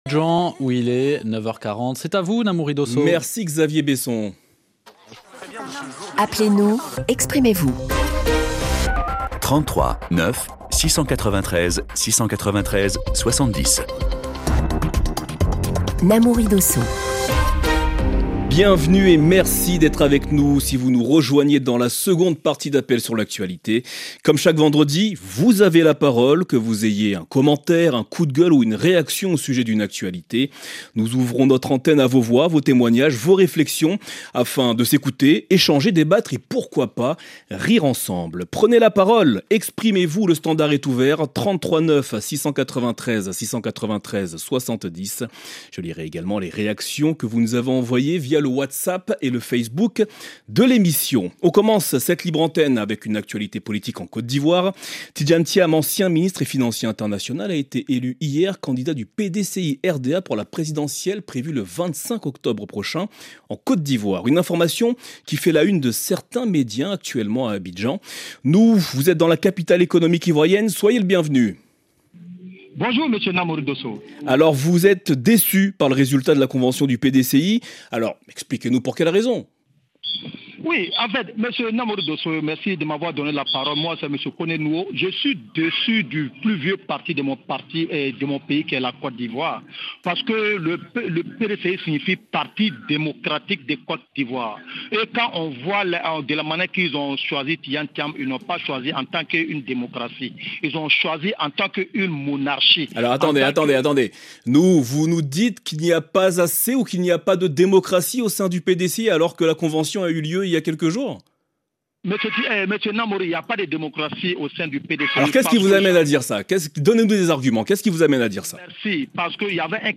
Le rendez-vous interactif des auditeurs de RFI.
L'émission est à la fois un lieu de décryptage de l'information grâce aux questions à la rédaction, mais aussi un lieu de débat où s'échangent idées et réflexions en provenance des cinq continents.